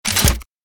Rocket Launcher Loading 3 Sound Effect Download | Gfx Sounds
Rocket-launcher-loading-3.mp3